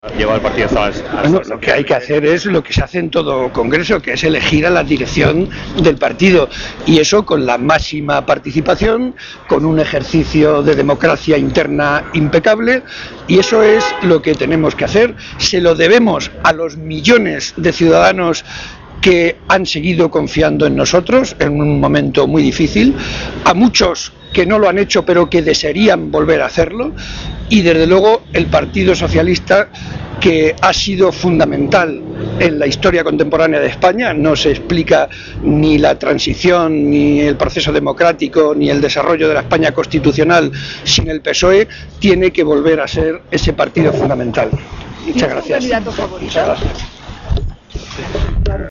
Barreda, que realizó estas manifestaciones a la entrada al Comité Federal que los socialistas están celebrando hoy en Madrid, argumentó que hemos sufrido una derrota muy severa que, además, está precedida de una derrota también muy importante en las elecciones municipales y autonómicas.
Cortes de audio de la rueda de prensa